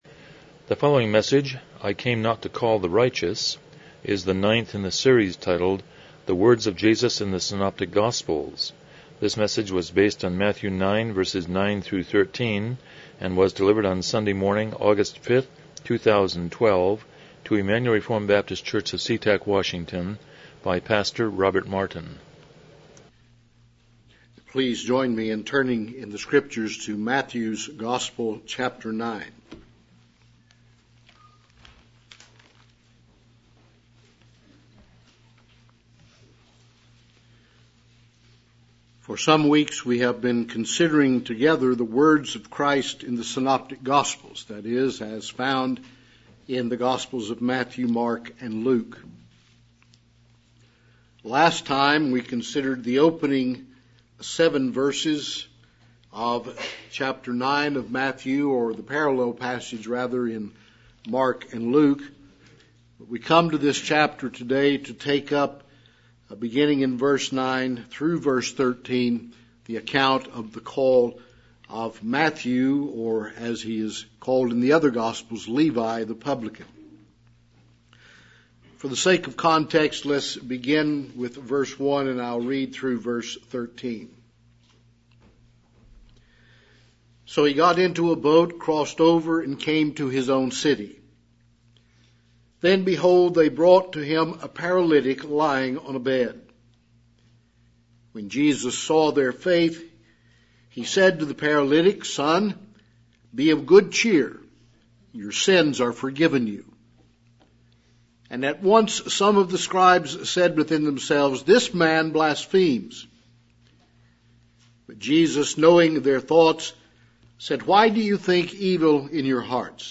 Passage: Matthew 9:9-13 Service Type: Morning Worship